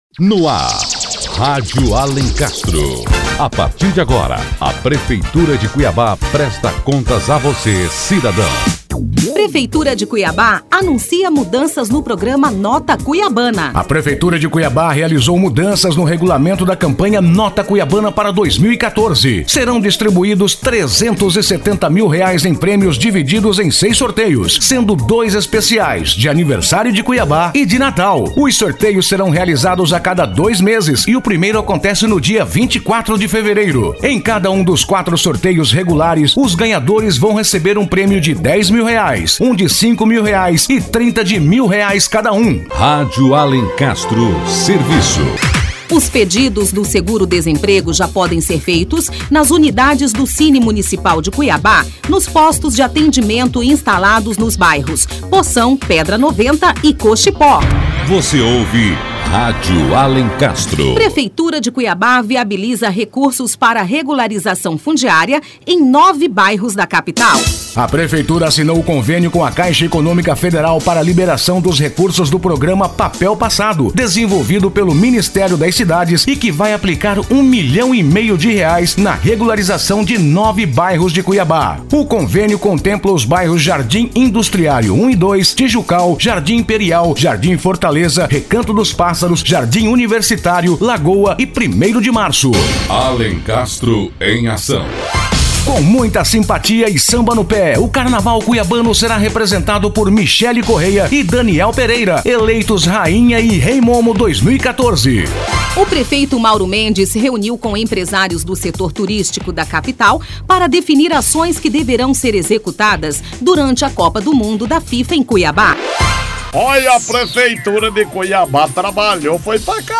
Conversa com o Secretário Municipal de Esportes, Cidadania e Juventude | Notícias - Prefeitura de Cuiabá